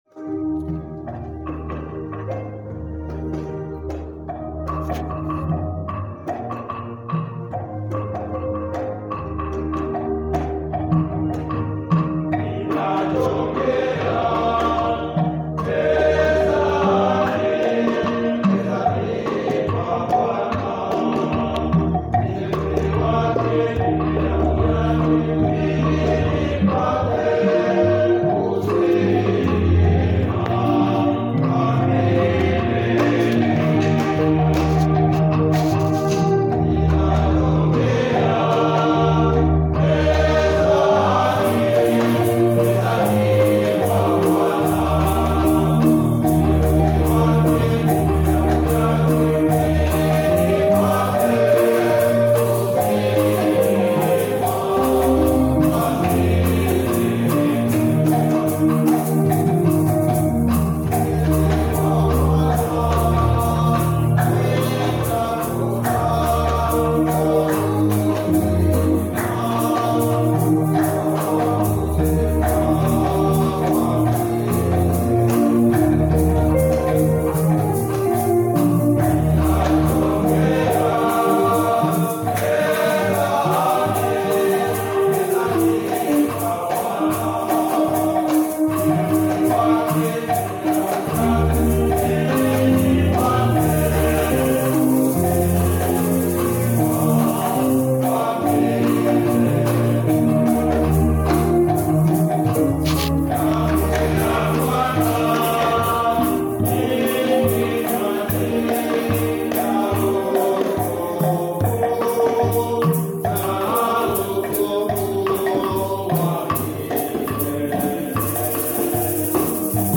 Chants de Communion Téléchargé par